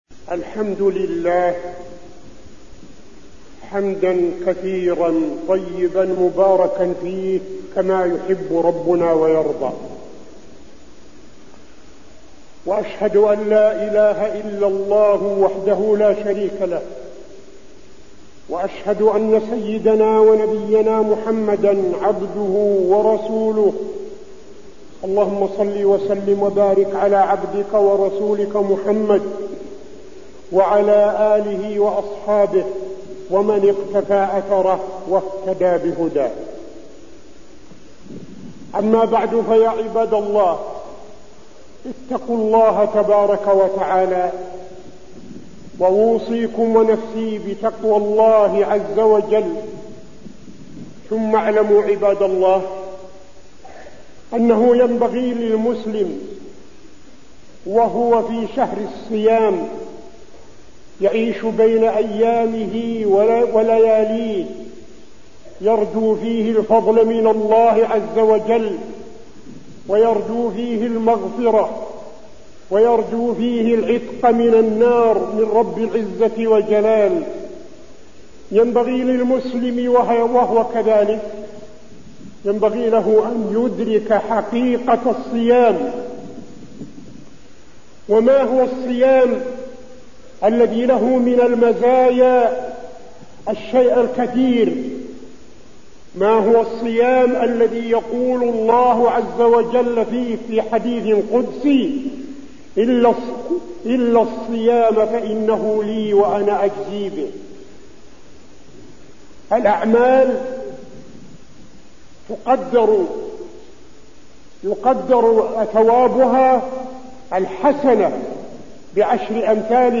تاريخ النشر ١٠ رمضان ١٤٠٧ هـ المكان: المسجد النبوي الشيخ: فضيلة الشيخ عبدالعزيز بن صالح فضيلة الشيخ عبدالعزيز بن صالح الصيام عن الذنوب والمعاصي